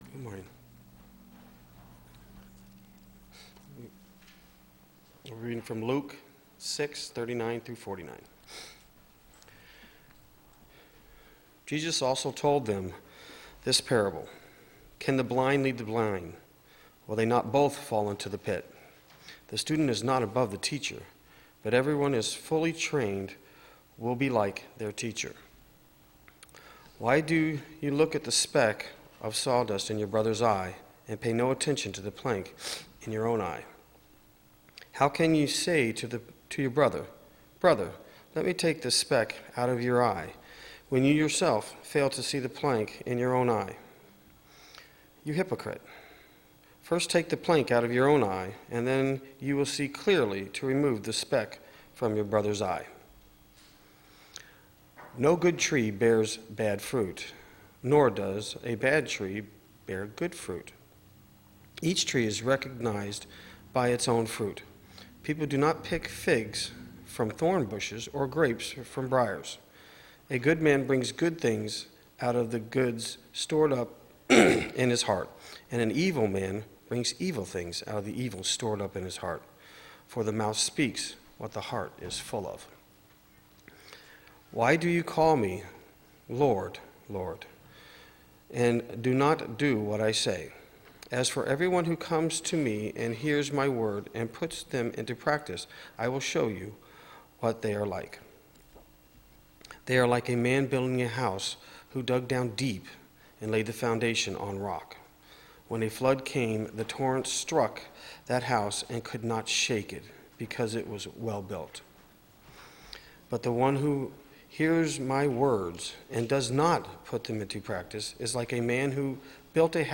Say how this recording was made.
Audio Recording of Sept. 6th Worship Service – Now Available The audio recording of our latest Worship Service is now available.